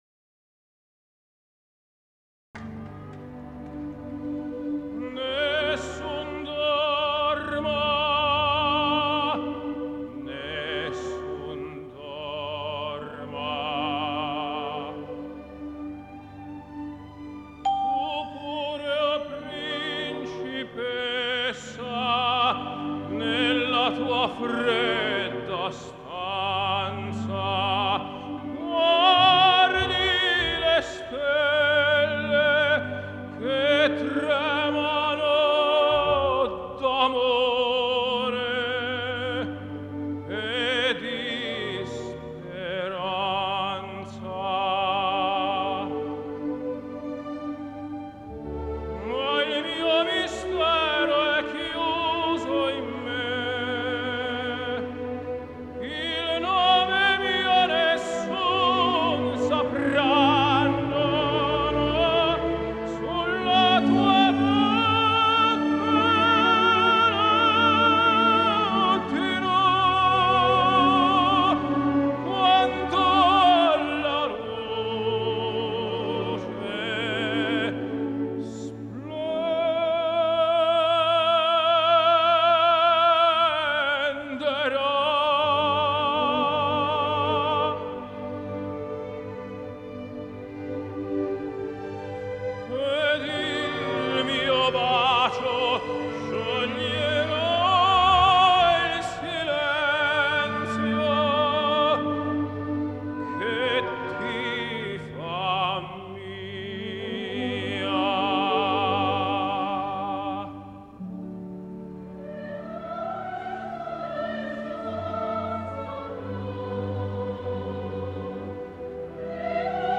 歌剧《图兰朵》中著名咏叹调
三幕歌剧
卡拉夫的咏叹调